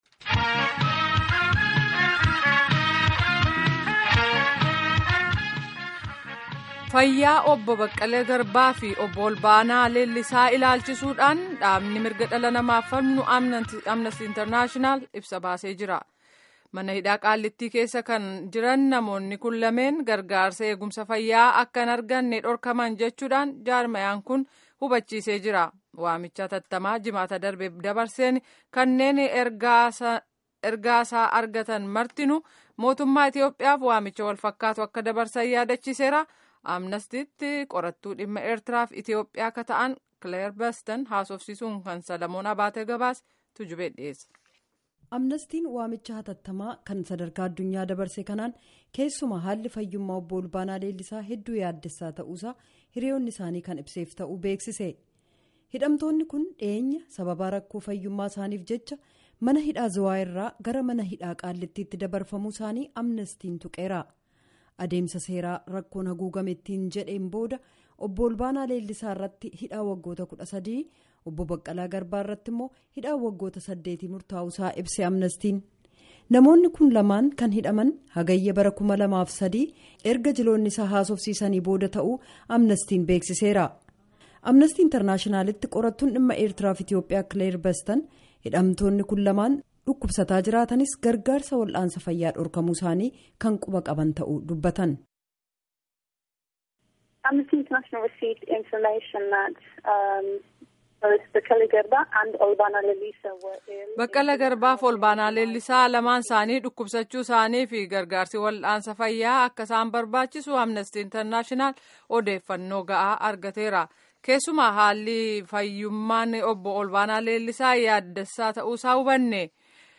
Gabaasaa Guutuu Armaa Gaditti Caqasaa